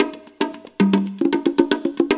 CONGA BEAT34.wav